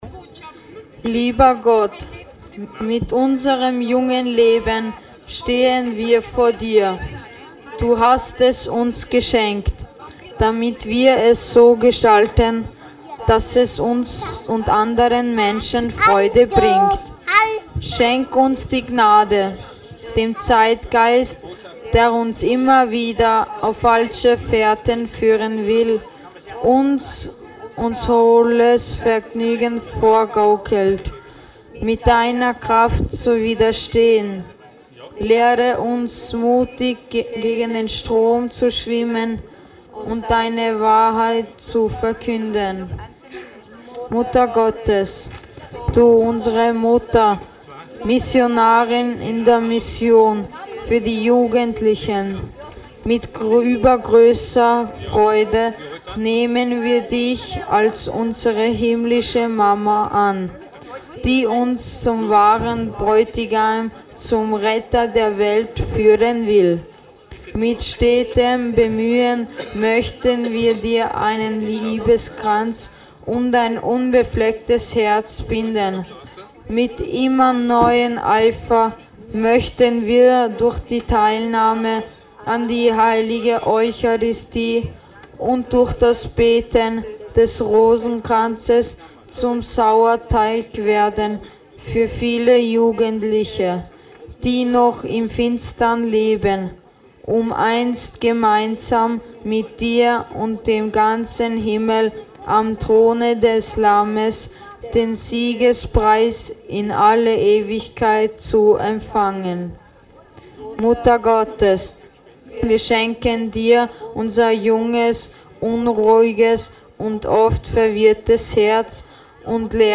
GEBETE
Bad St. Leonhard in Kärnten/Österreich
betet das Jugendgebet zusammen mit Jugendlichen und Kindern.
Jugendgebet.mp3